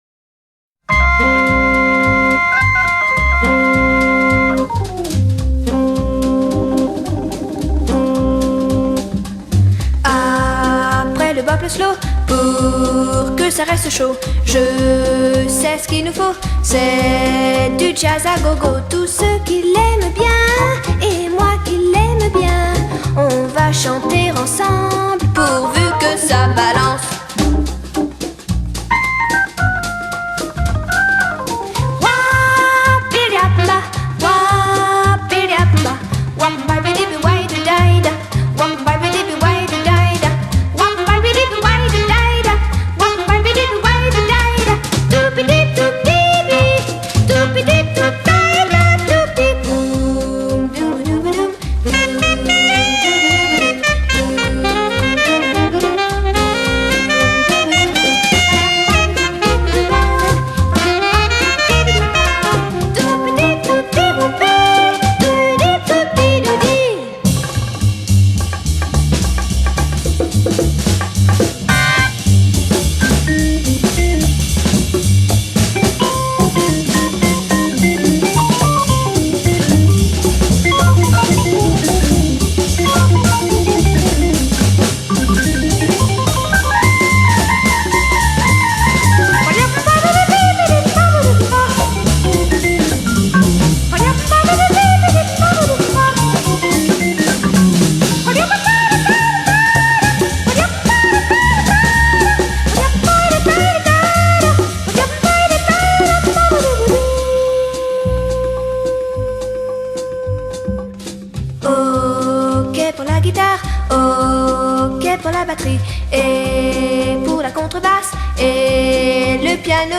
йе-йе, музыкальный стиль, жанр французской музыки 1960х